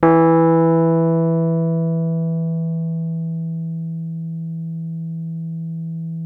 RHODES CL08L.wav